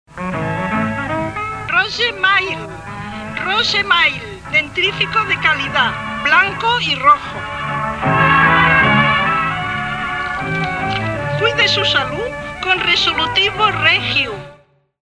Falca publicitària - Ràdio Badalona, 1945